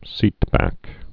(sētbăk)